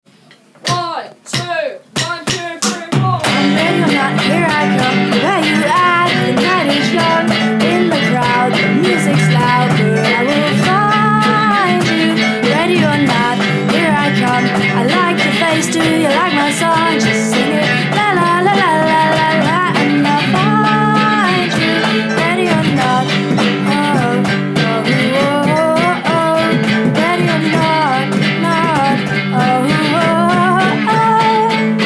ready or not group recording chorus